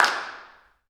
OAK_clap_mpc_02.wav